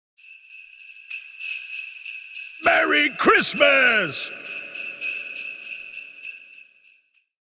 Santa Says Merry Christmas With Sleigh Bells Ringing – Holiday Sound Effect
Santa says “Merry Christmas” with cheerful sleigh bells ringing in the background.
Add it to your project to create a joyful and seasonal atmosphere.
Santa-says-merry-christmas-with-sleigh-bells-ringing-holiday-sound-effect.mp3